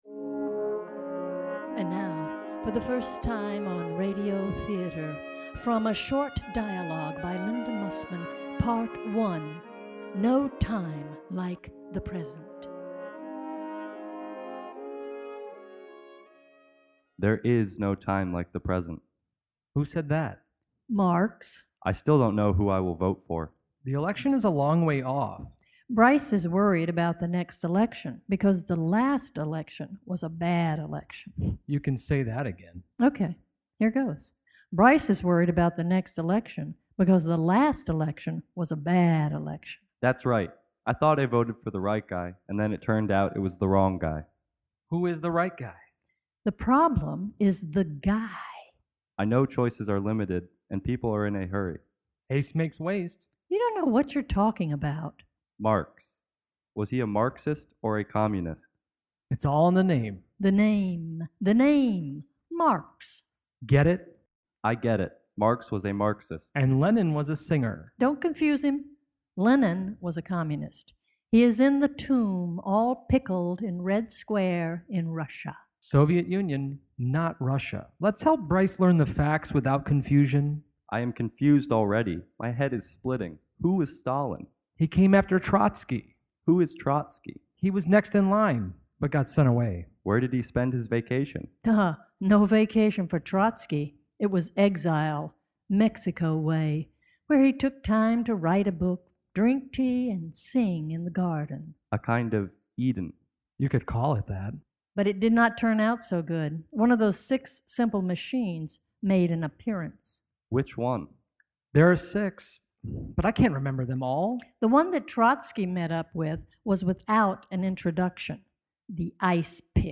TSL RADIO THEATER - A SHORT DIALOGUE, NO TIME LIKE THE PRESENT RADIO EDIT 2.mp3